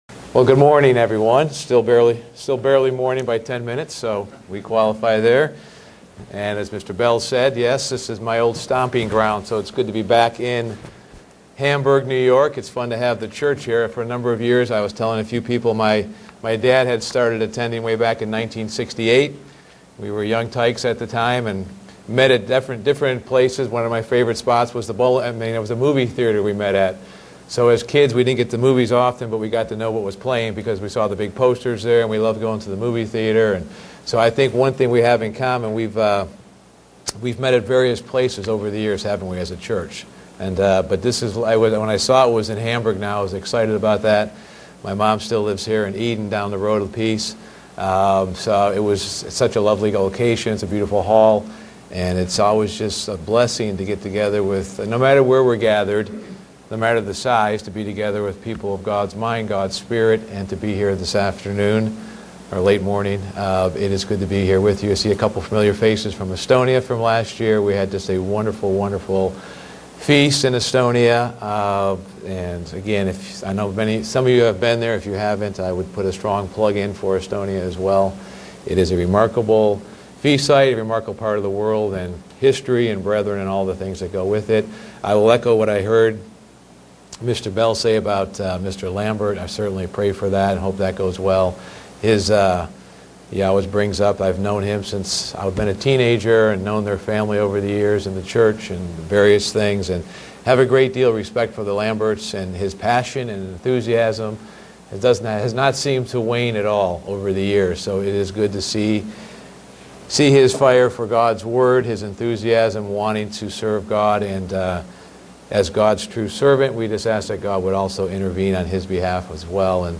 Print Having total Faith is a process and will help to over come Fear. sermon Studying the bible?